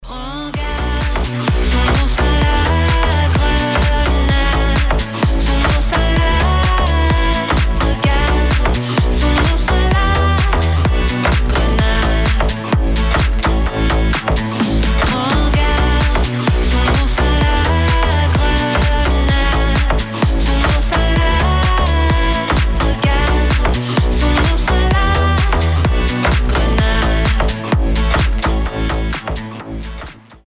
• Качество: 320, Stereo
deep house
женский голос